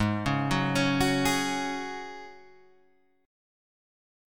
G#7sus4 chord